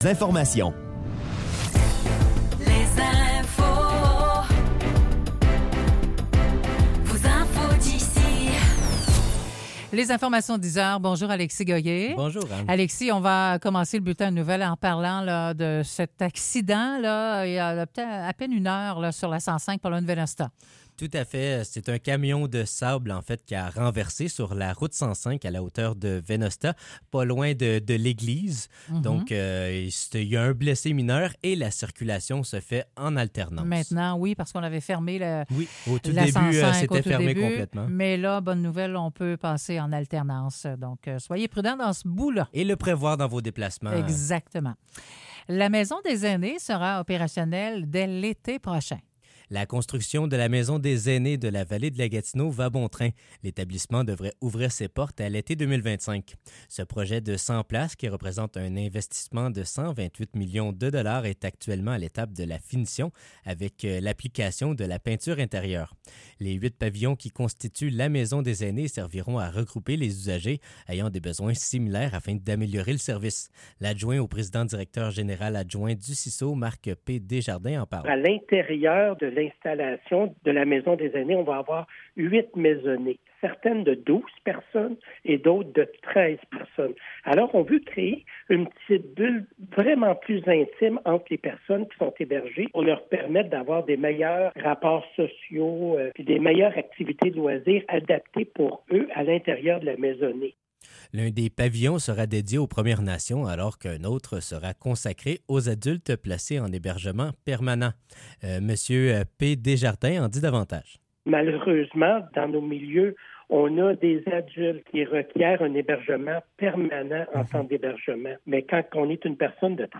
Nouvelles locales - 23 octobre 2024 - 10 h